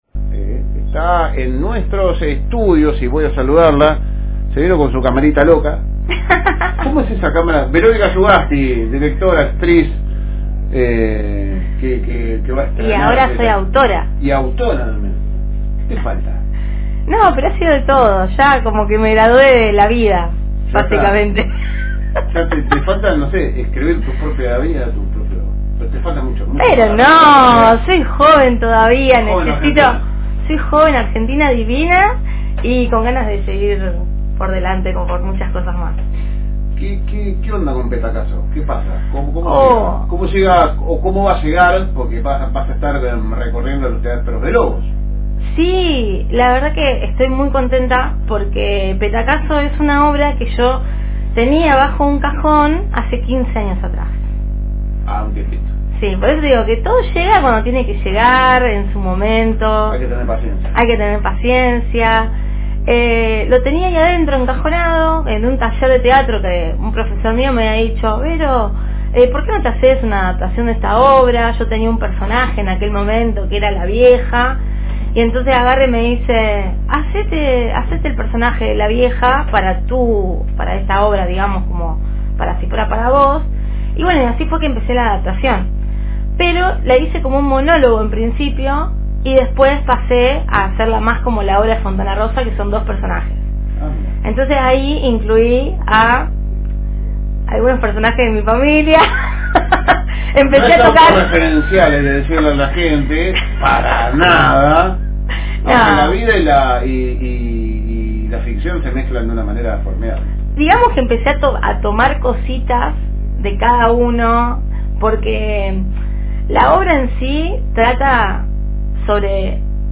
Por los estudios de la radio paso